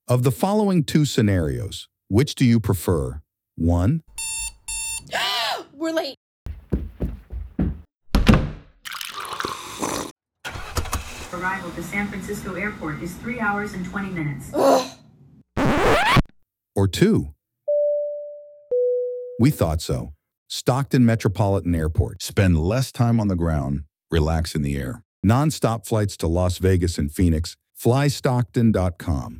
Radio Ad For Maximized Attention and Efficiency
This radio ad spot aired on two channels in the Stockton/Modesto, California area in September and October.